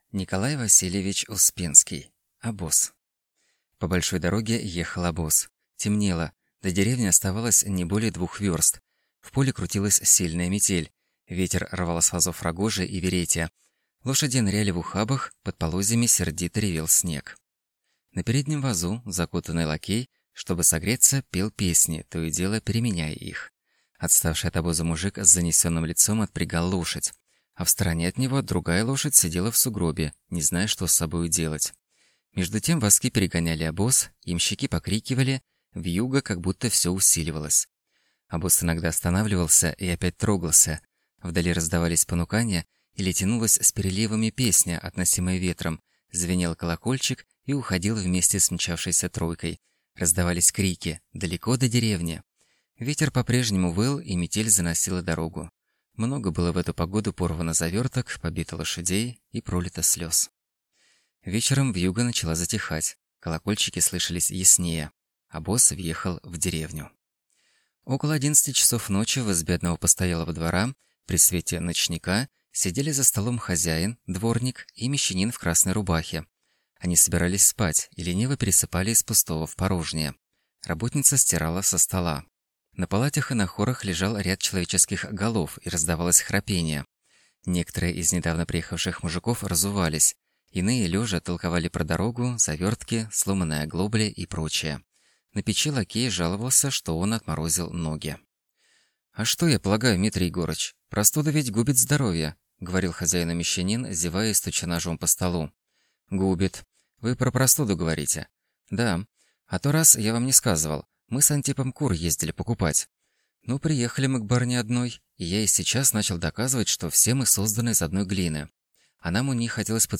Аудиокнига Обоз | Библиотека аудиокниг